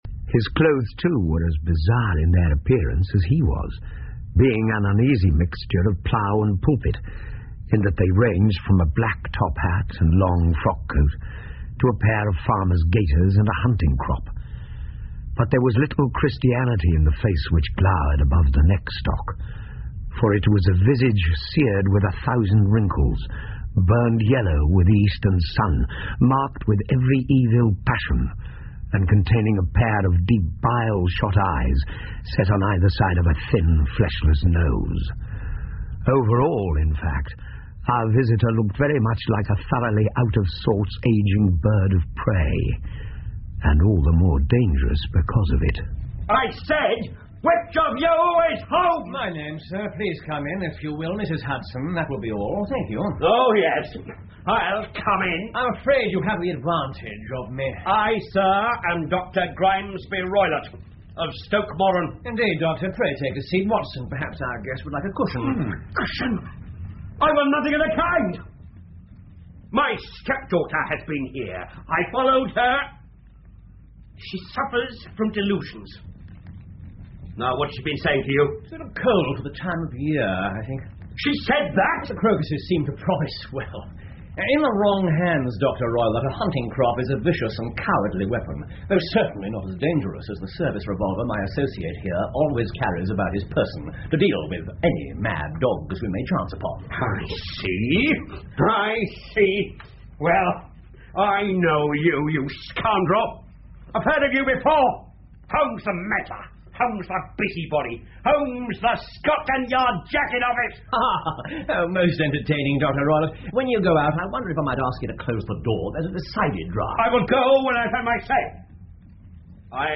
福尔摩斯广播剧 The Speckled Band 5 听力文件下载—在线英语听力室